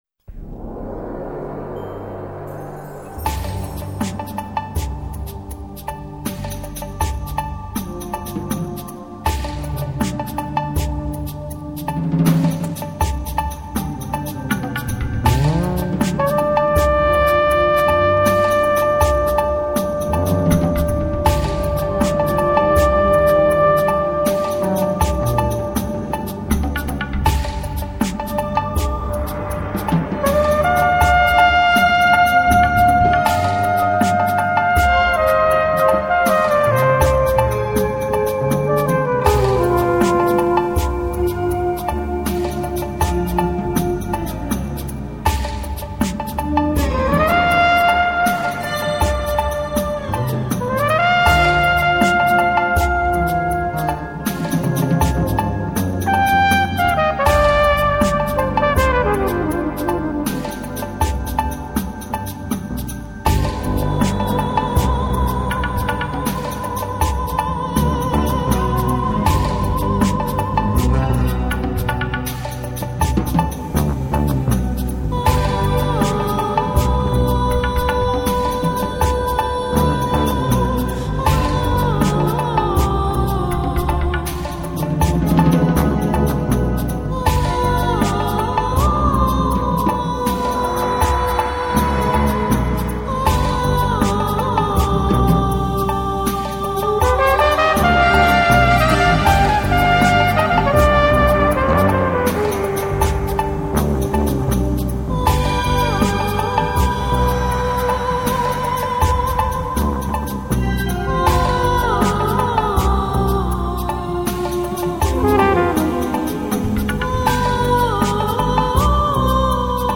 1997 Musiche di scena